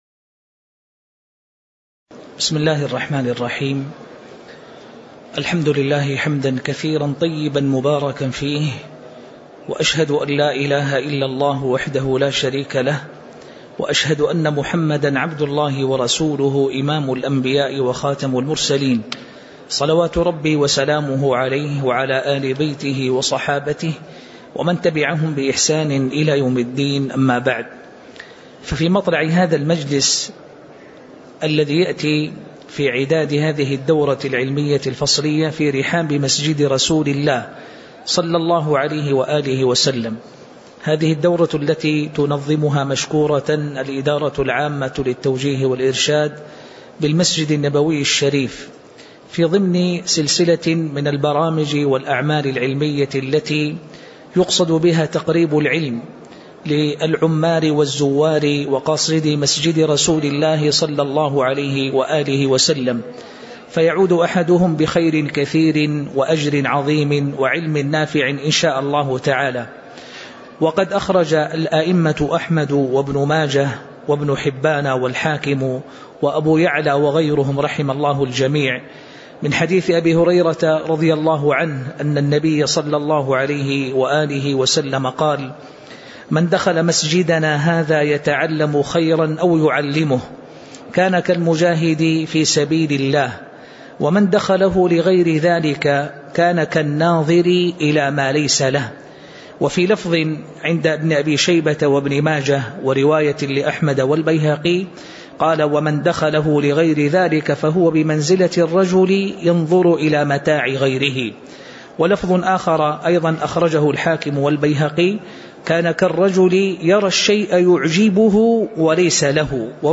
تاريخ النشر ٢٦ ربيع الثاني ١٤٣٩ هـ المكان: المسجد النبوي الشيخ